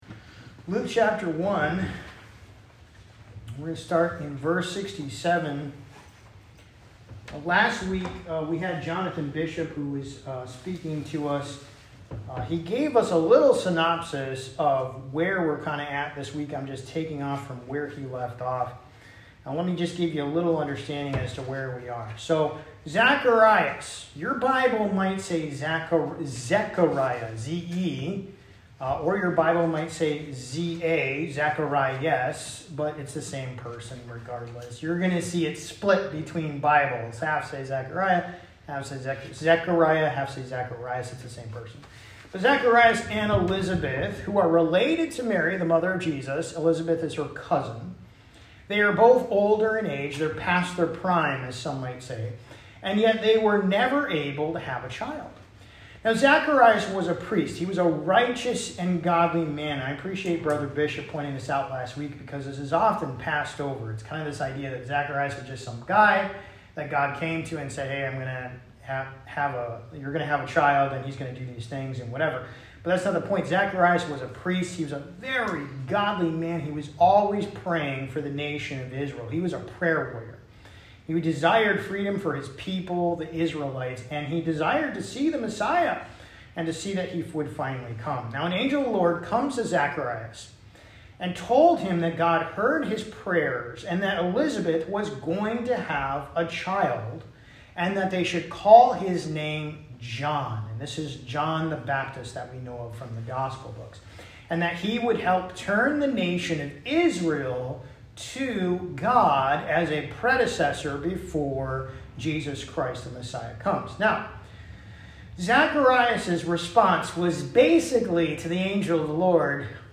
Sermons | Campbell Bible Church